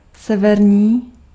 Ääntäminen
US : IPA : /ˈnɔɹ.ðɚn/ UK : IPA : /ˈnɔːðn̩/ IPA : /ˈnɔː.ðən/